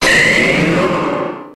Grito de Mega-Venusaur.ogg
Grito_de_Mega-Venusaur.ogg